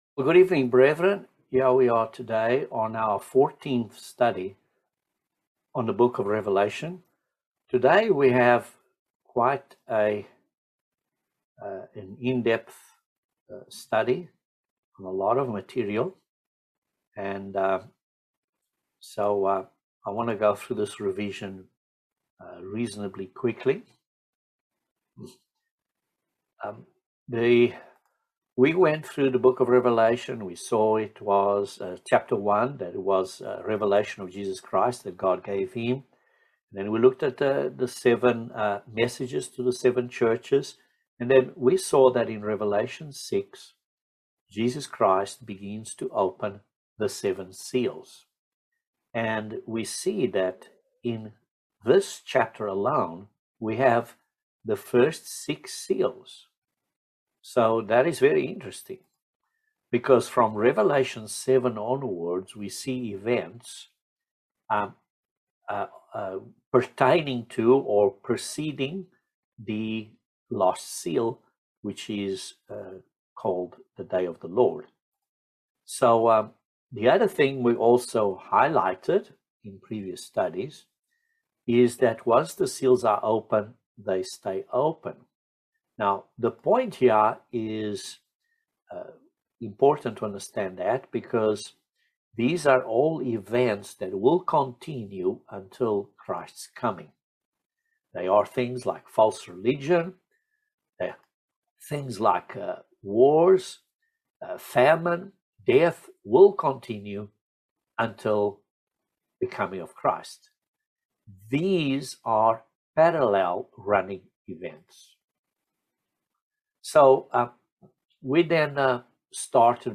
Bible Study No 14 of Revelation